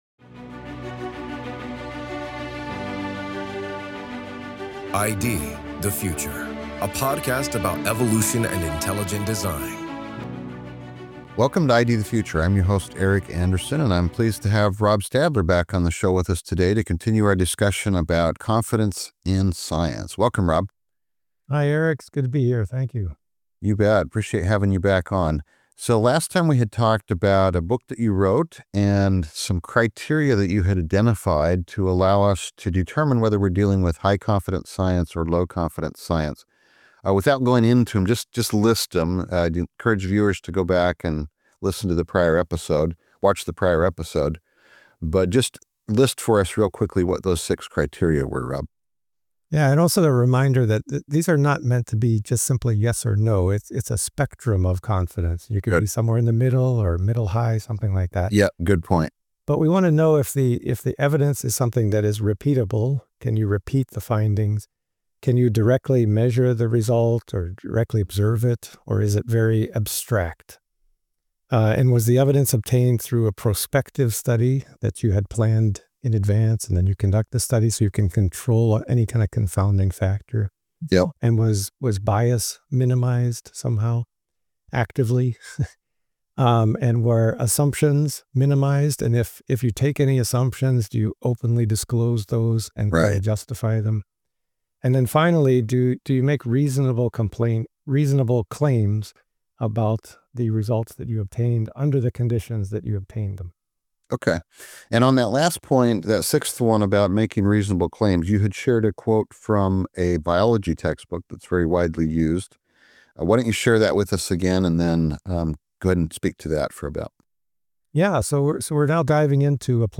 This is Part 2 of a two-part conversation.